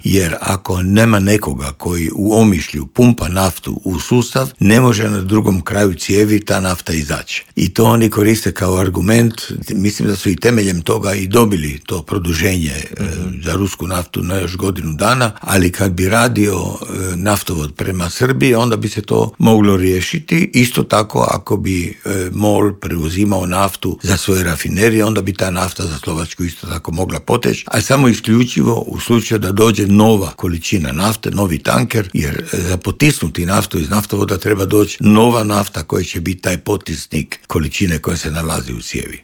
ZAGREB - Dok svijet pozorno prati hoće li doći do mirnog okončanja rata u Ukrajini, energetski stručnjak Davor Štern u Intervjuu Media servisa poručuje da bi u slučaju ublažavanja europskih sankcija trebalo dogovoriti fiksirane cijene nafte i plina.